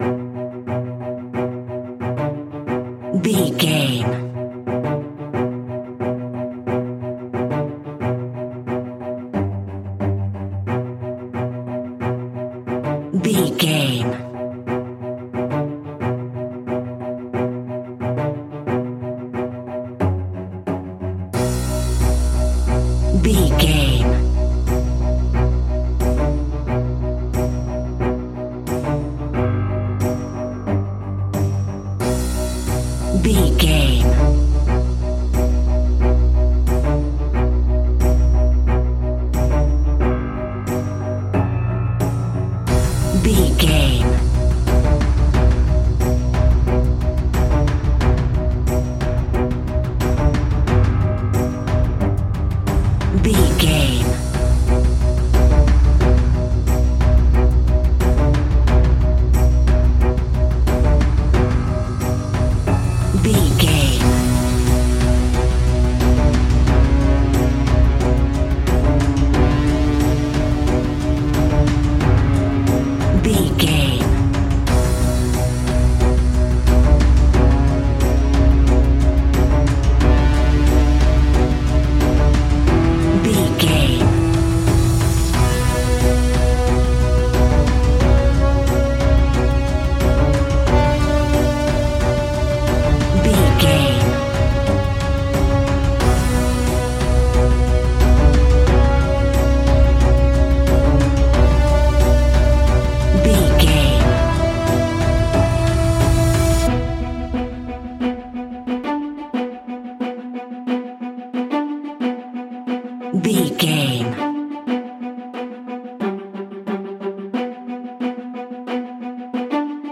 In-crescendo
Thriller
Aeolian/Minor
ominous
dark
haunting
eerie
strings
percussion
piano
drums
ticking
electronic music